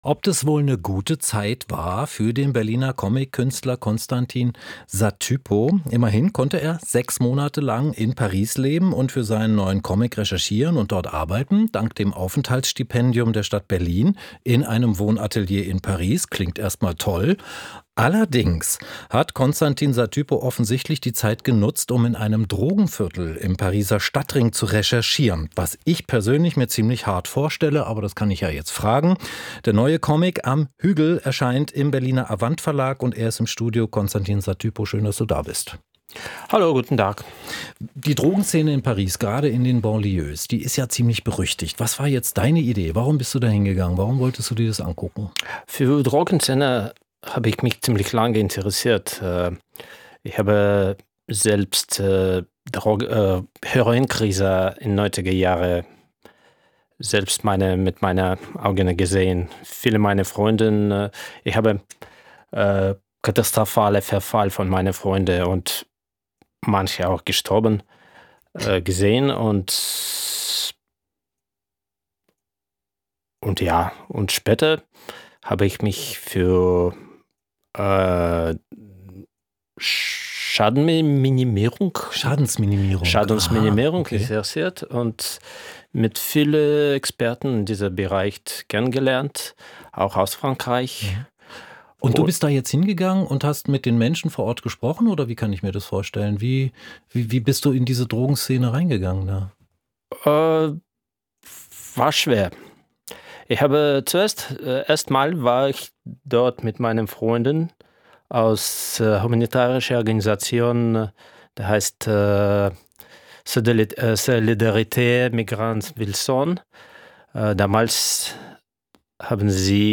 Comic